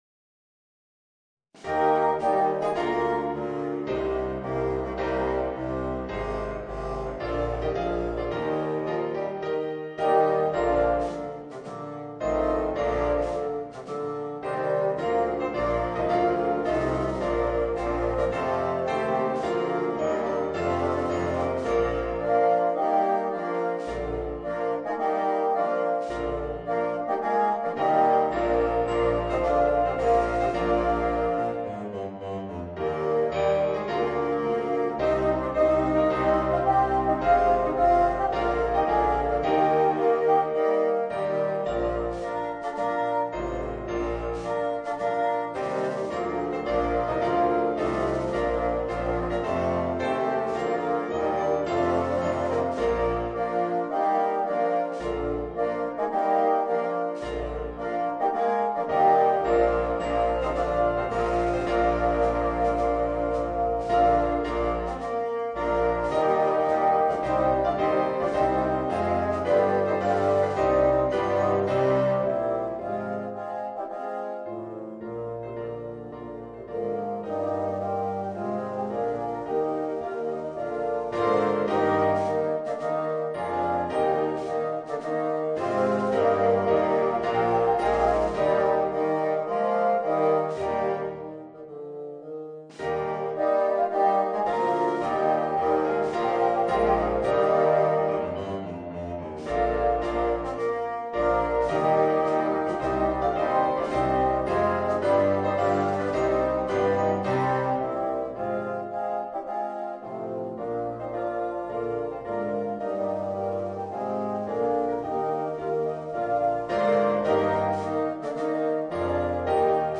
Voicing: 8 Bassoons